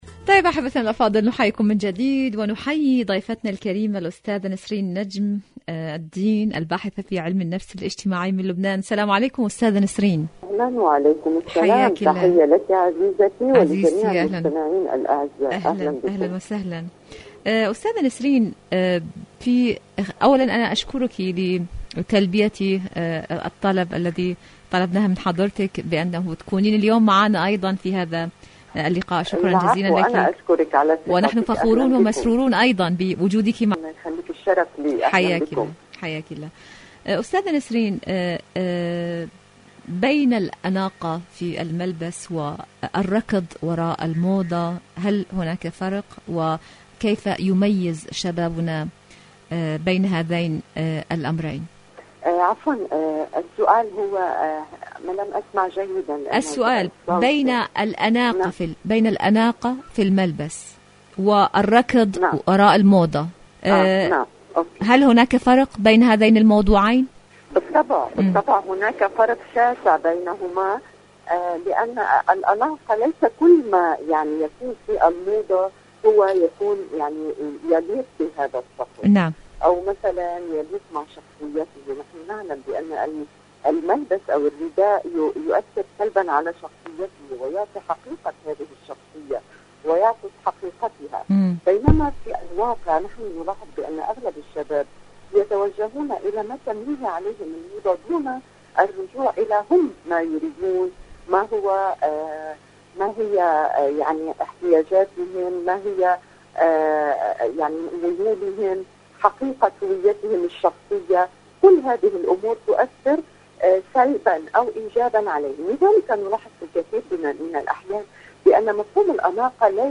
مقابلات برامج إذاعة طهران برنامج دنيا الشباب الشباب مقابلات إذاعية الملبس اختيار الملبس أنا أختار ملبسي ولكن..